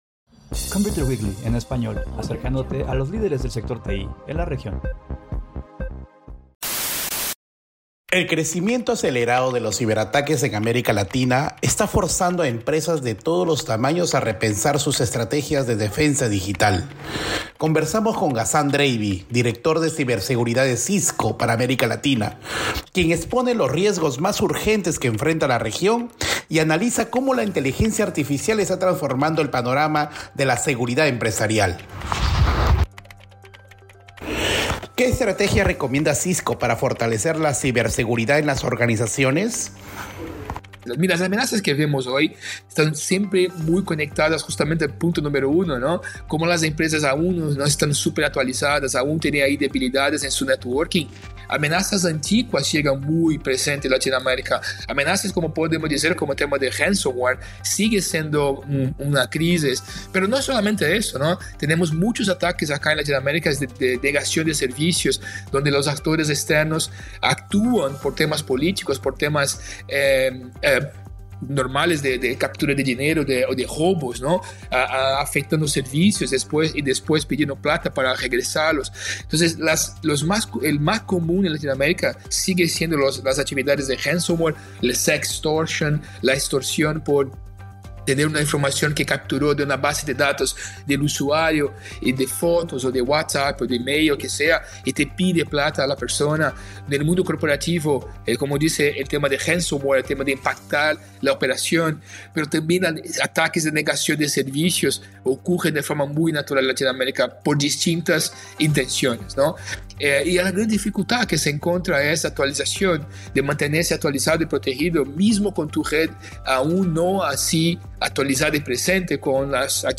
La problemática de las viejas brechas de seguridad subsiste, y el ransomware se mantiene como un peligro constante en América Latina. En entrevista con ComputerWeekly en Español